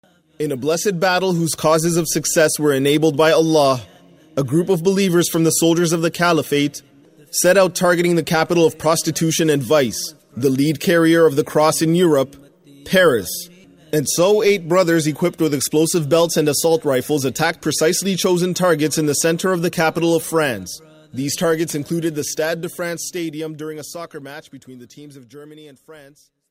Experts analyzing the voice feel that it belongs to someone who learned English in Canada or perhaps in a northern US state.
Shortened recording of ISIS message claiming responsibility for the Paris murders.  Canadian accent?
In this latest case however, audio only, the speaker was not identified.